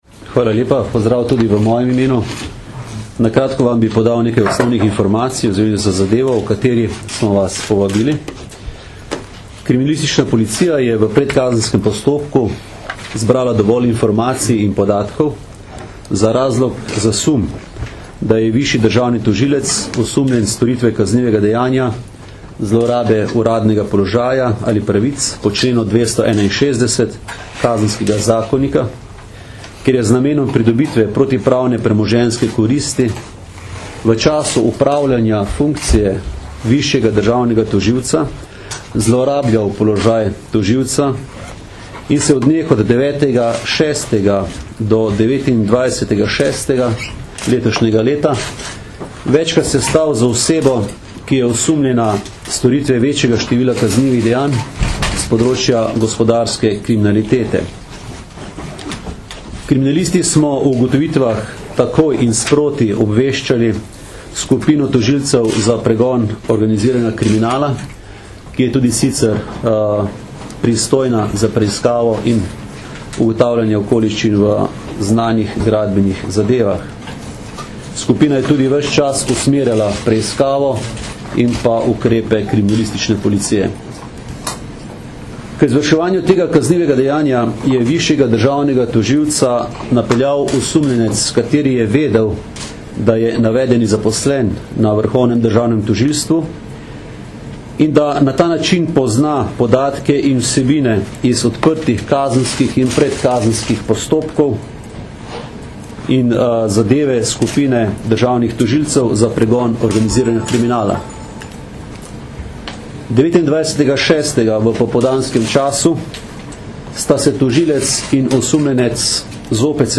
Zvočni posnetek izjave mag. Aleksandra Jevška (mp3)